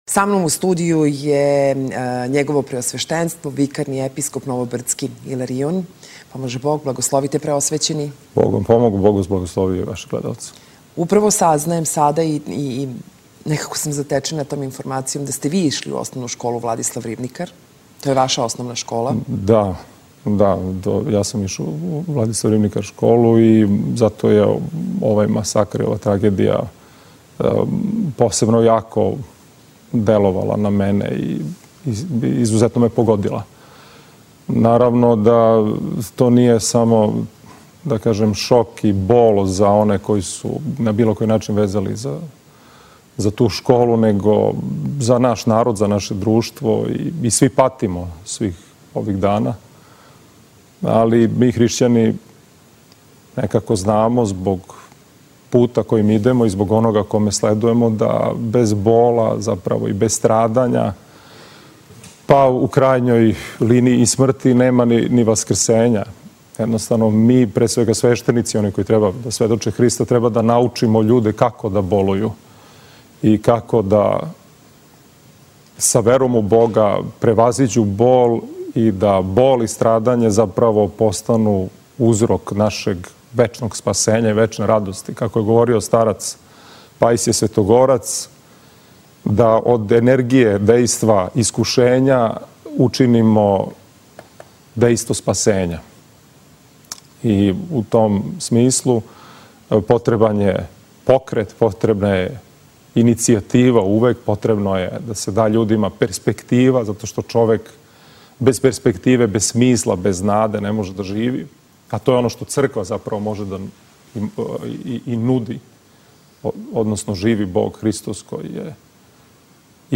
Уочи славе Града Београда, празника Вазнесења Господњег, Епископ новобрдски Иларион позвао је Београђане да учествују у светој Литургији и славској литији, са искреном љубављу према ближњима и надом да ће управо та љубав бити наша највећа заједничка нада. Звучни запис разговора